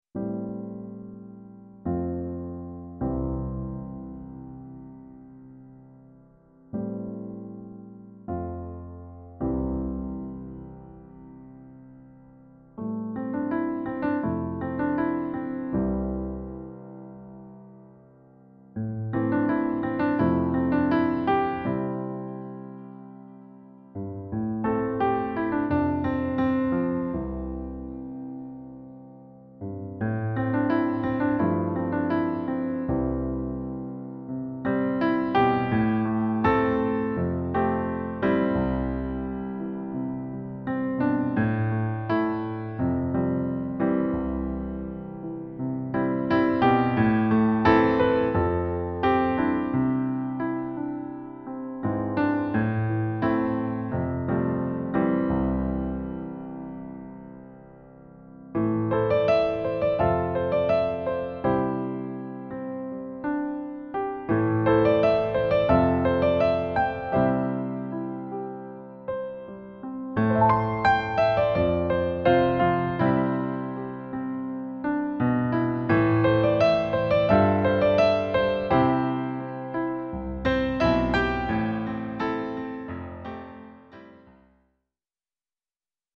Ihre Trau(m)-Songs wunderschön am Piano gespielt
Hier einige Hörproben des Hochzeits-Pianisten (mp3):